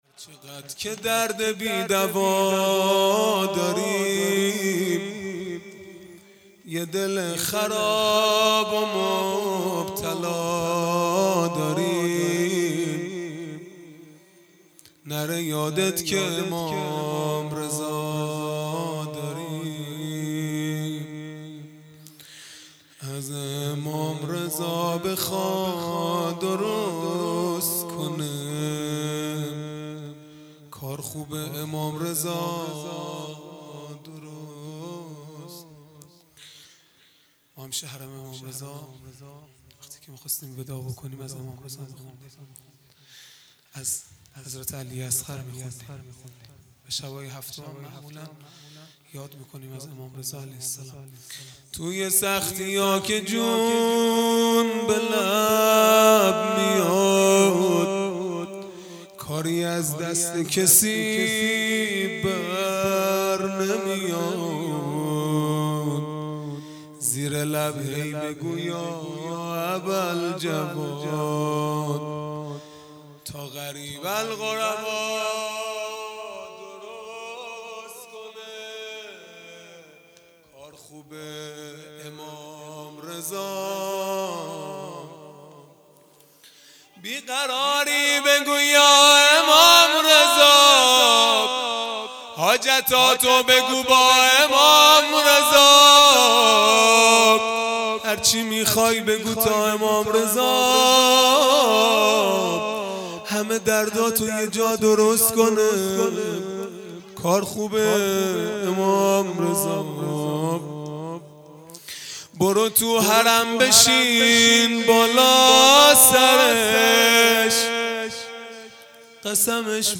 مناجات پایانی | چقد که درد بی دوا داری | یک شنبه ۲۴ مرداد ۱۴۰۰
دهه اول محرم الحرام ۱۴۴۳ | شب هفتم | یک شنبه ۲۴ مرداد ۱۴۰۰